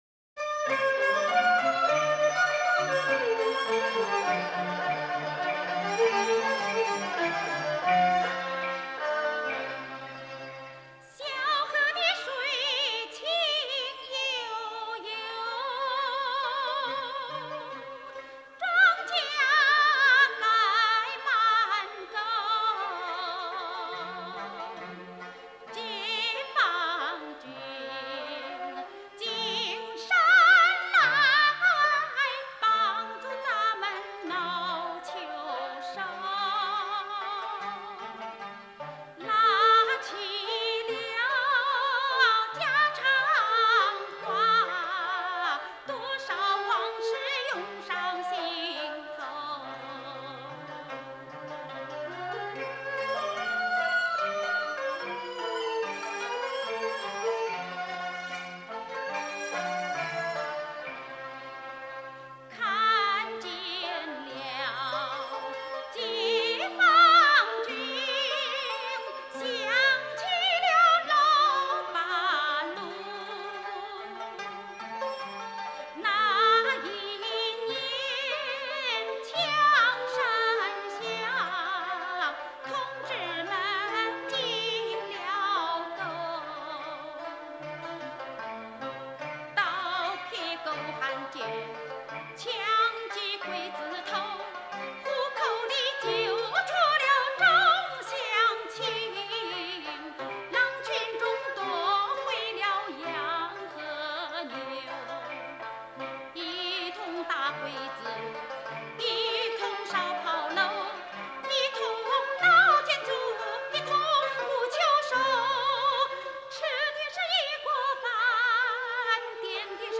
1966年录音
中国女高音歌唱家，音乐教育家，国务院政府特殊津贴专家。